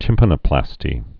(tĭmpə-nə-plăstē, -nō-)